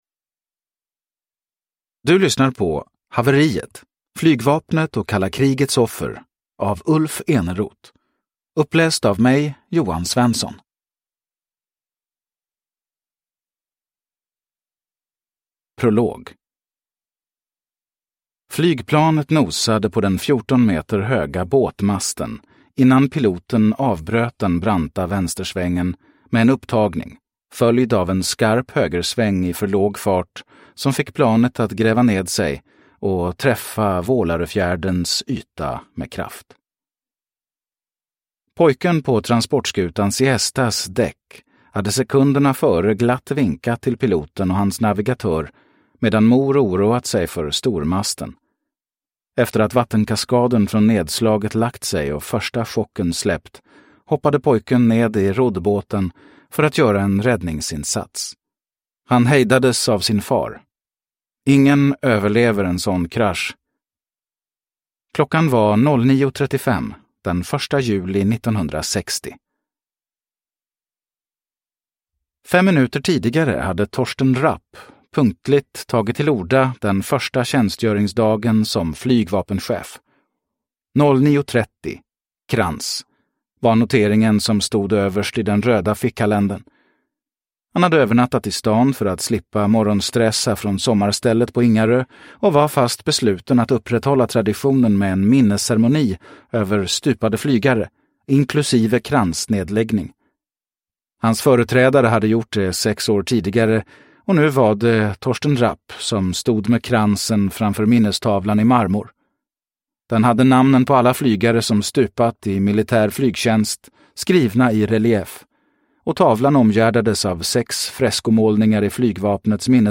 Haveriet : flygvapnet och kalla krigets offer – Ljudbok – Laddas ner